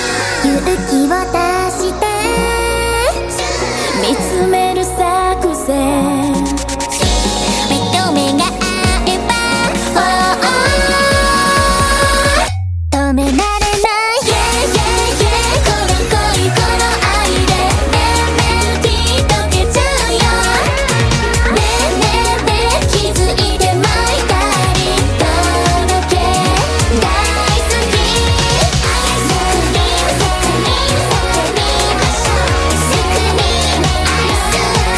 2025-01-21 Жанр: Танцевальные Длительность